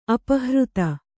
♪ apahřta